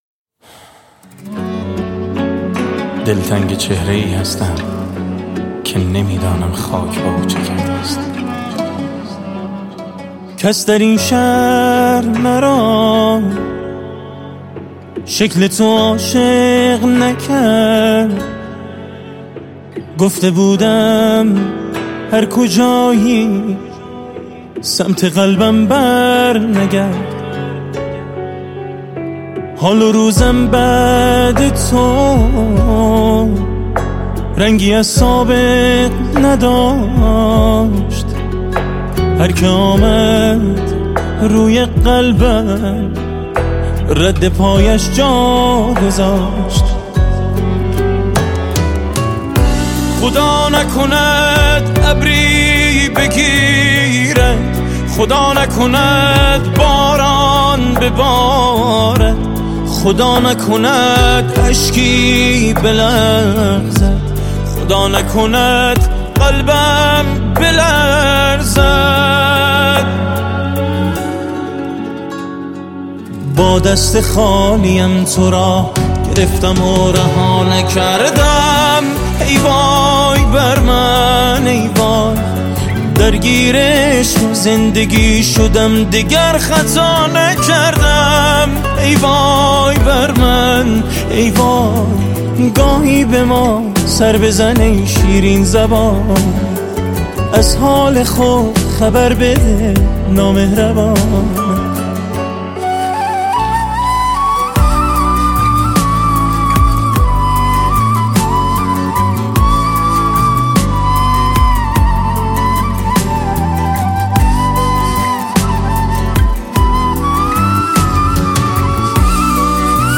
عالیه ، خیلی با احساس خونده
صداش مثله مسکنه
واقعا ترکوندی با این آهنگت بخصوص اونجاکه فلوت میزد.